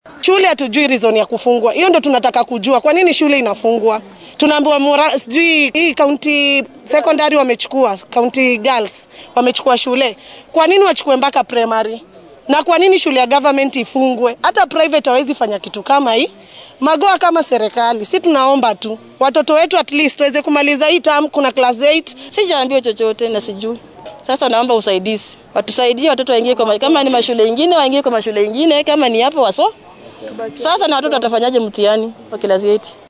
Waalidiinta oo warbaahinta la hadlay ayaa sidatan dareenkooda u muujiyay